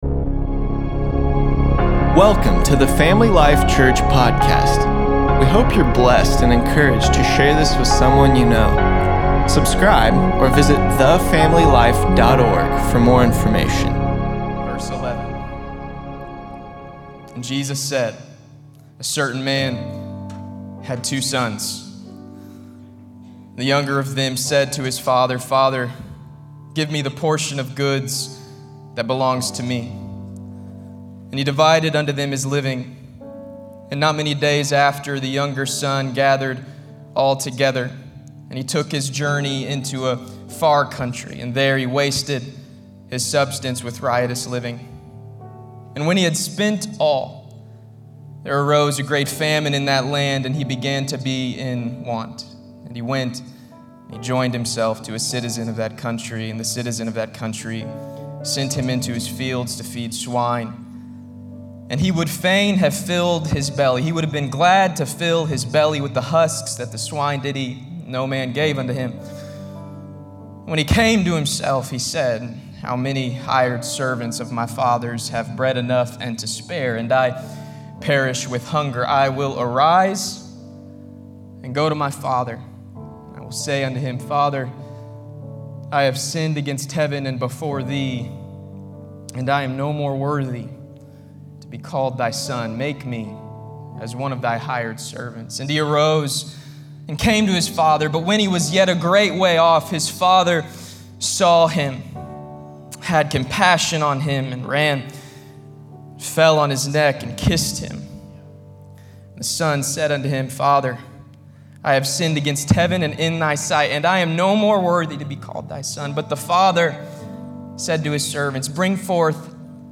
7-12-20_sermon_p.mp3